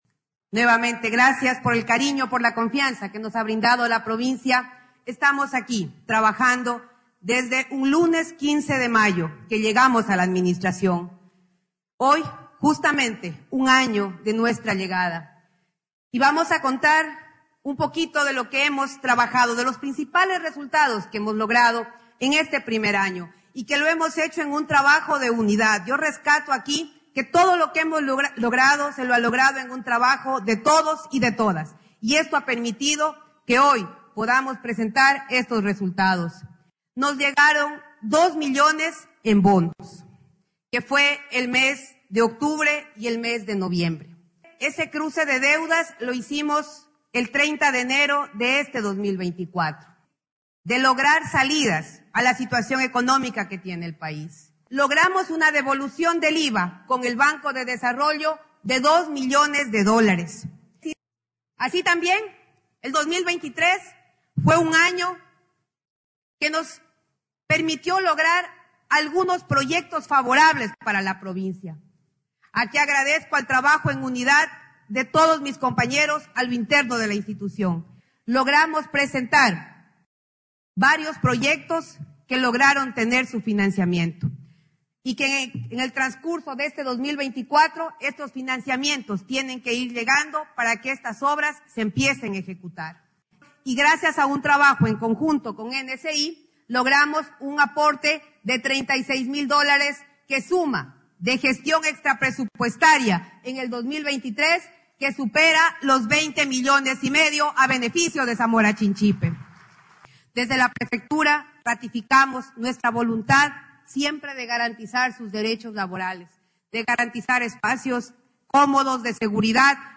KARLA REÁTEGUI, PREFECTA